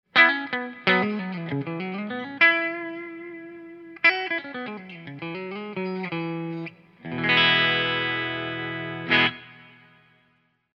Our River Bottom bridge Tele pickup is a vintage output single coil that's clear and twangy with excellent dynamics and attack. It has an open, articulate sound with amazing clarity, both through clean amps or through something with a little more grind.
Riverbottom Bridge Clean
Recording Specs: American Standard Tele (maple neck), EVH 5150 III, 4×12 Celestion V30’s, SM57